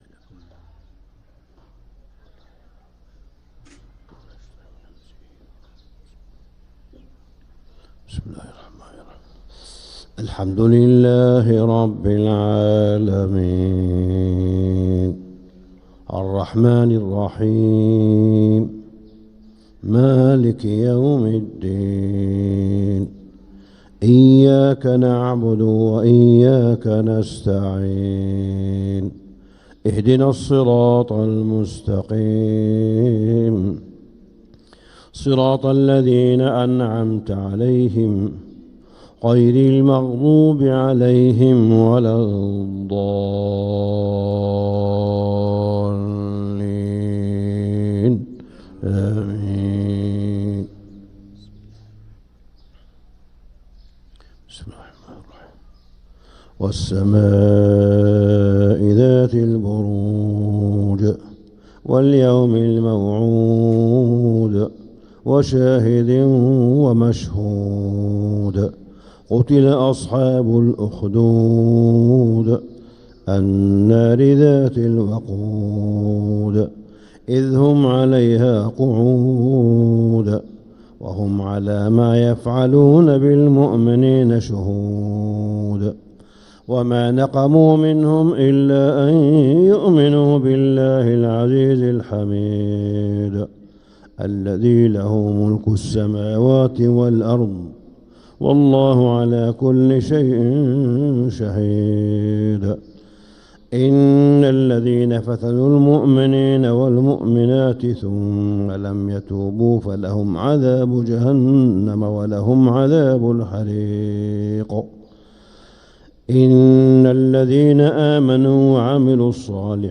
فجر الجمعة 8-8-1446هـ سورتي البروج و البلد كاملة | Fajr prayer Surat al-Buruj & al-Balad 7-2-2025 > 1446 🕋 > الفروض - تلاوات الحرمين